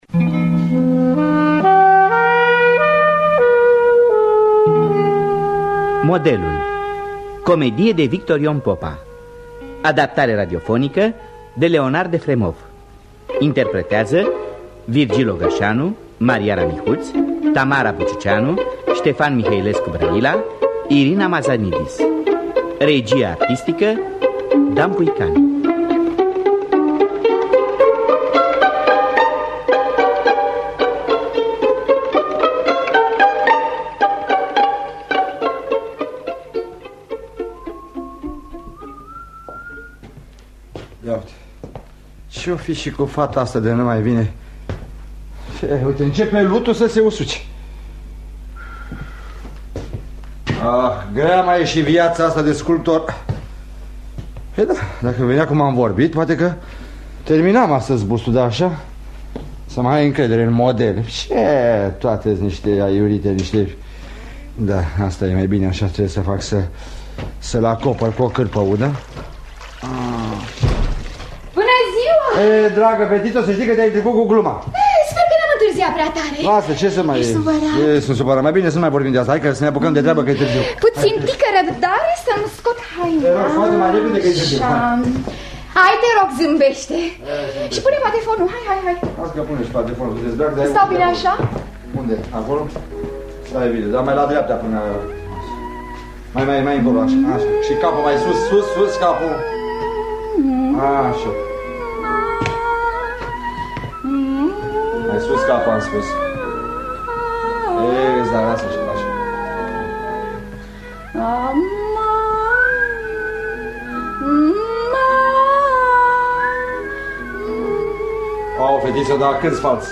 Modelul de Victor Ion Popa – Teatru Radiofonic Online
Adaptarea radiofonică